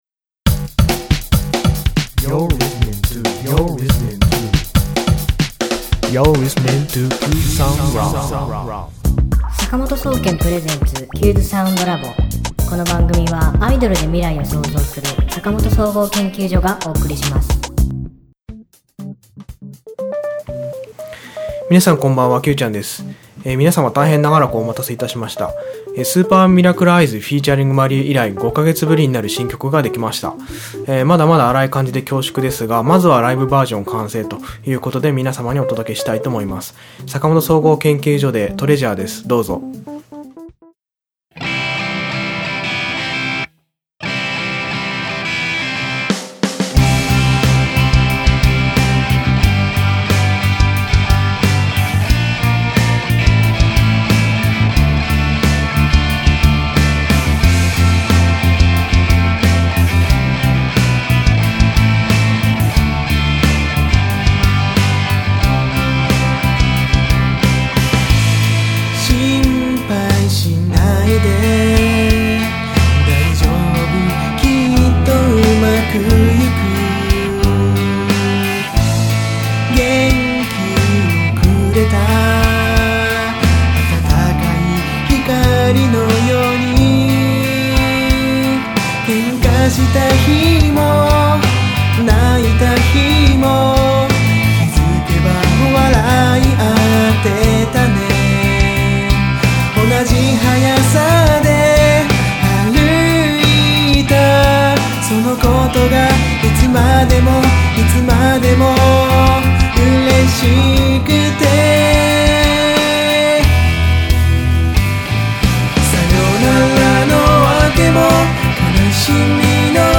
ああ、やっとできましたよ…。スピッツ風。
今週の挿入歌